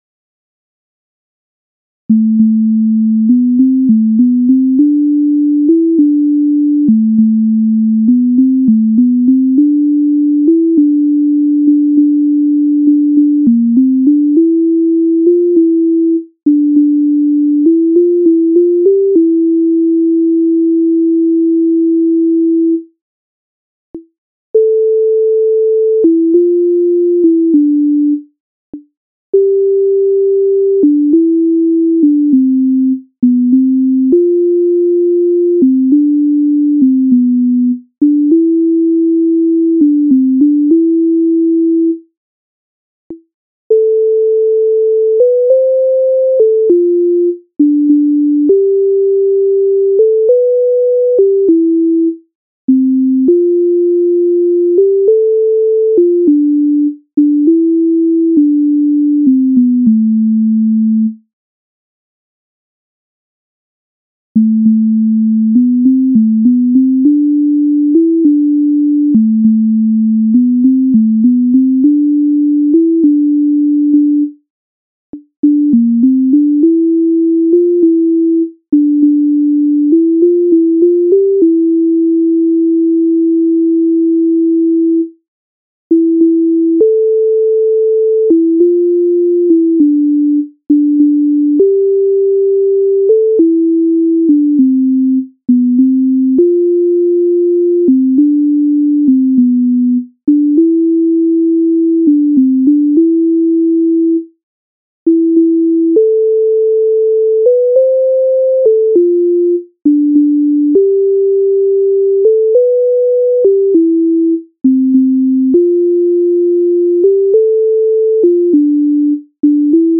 MIDI файл завантажено в тональності a-moll